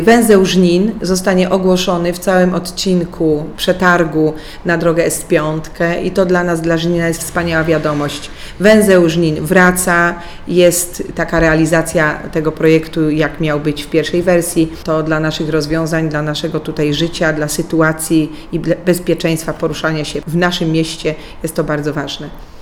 Mówiła wiceburmistrz Żnina Aleksandra Nowakowska.